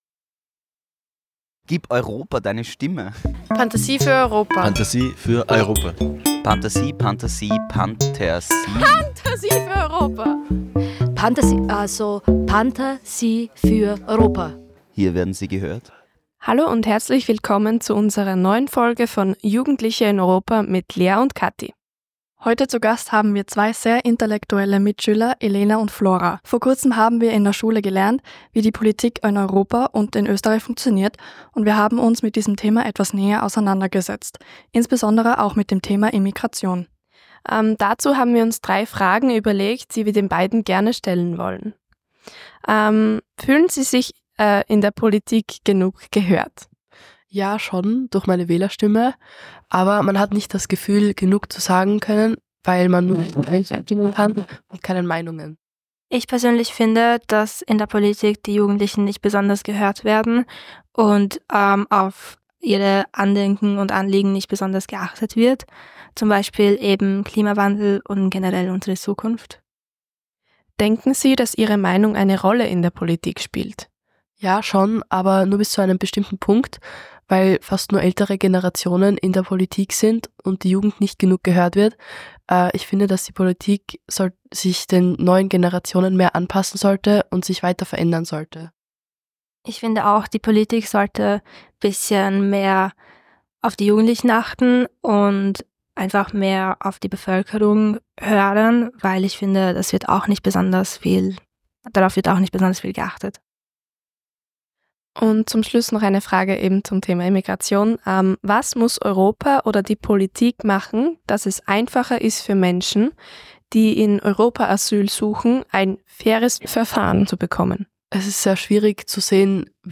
Straßenumfrage Hartberg Asyl